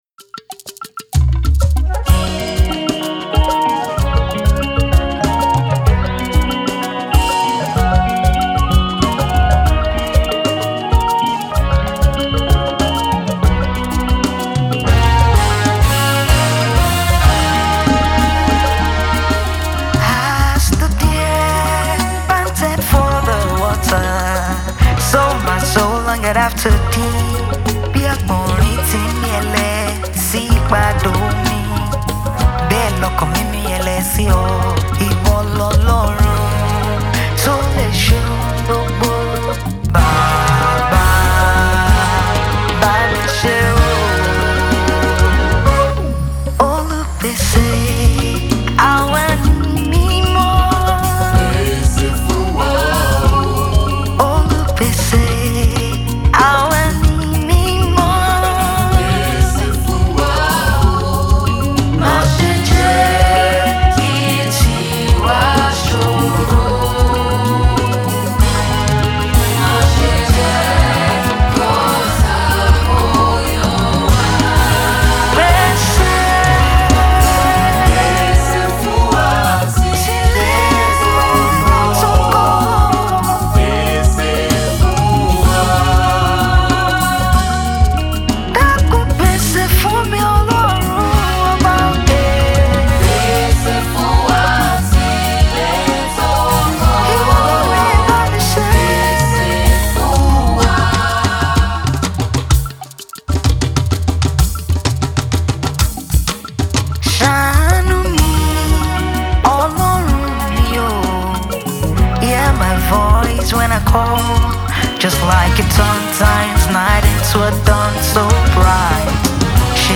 gospel
With her soothing vocals and anointing-filled delivery
a calm, reflective sound
The production is clean and intentional